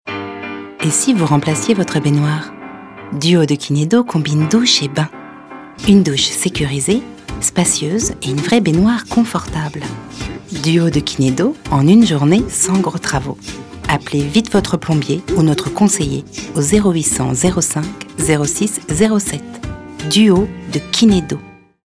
Bandes-son
Pub Duo Kinédo